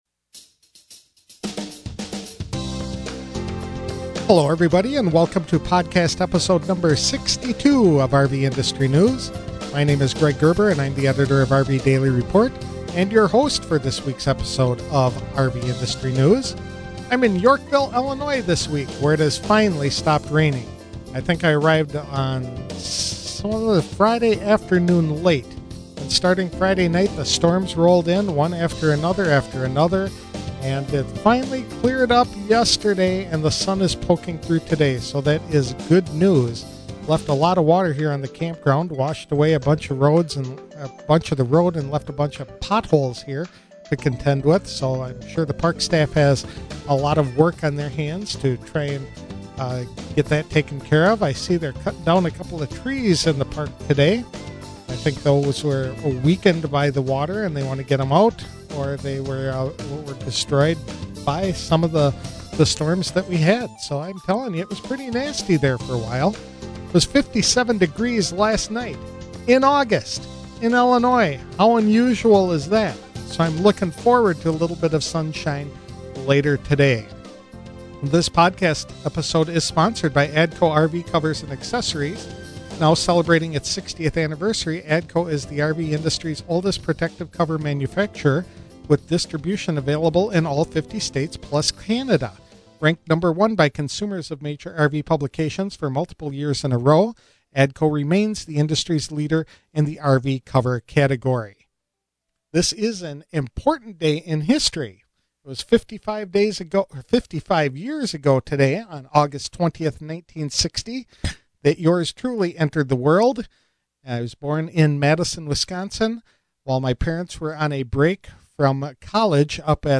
The interview gets going at 7:45 into the recording.